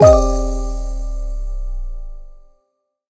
menu-multiplayer-click.ogg